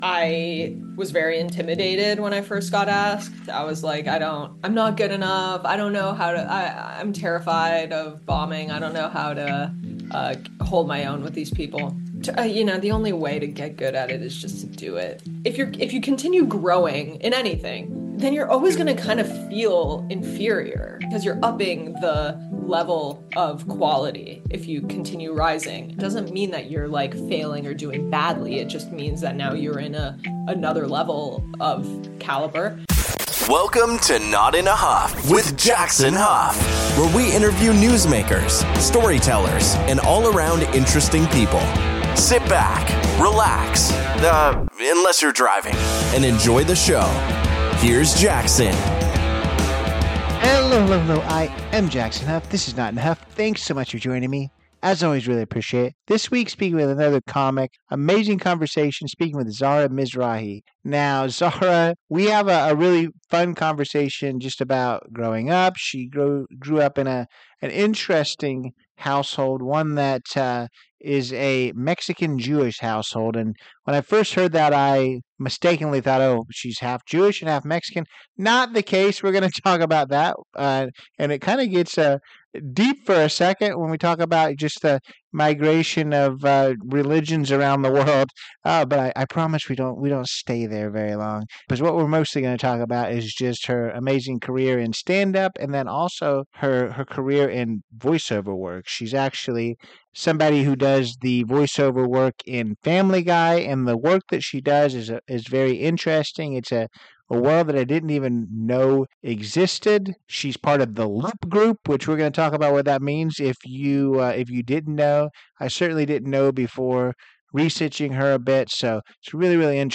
With rapid-fire questions, plenty of laughs, and behind-the-scenes stories from the world of comedy and voice acting, this conversation is as entertaining as it is enlightening.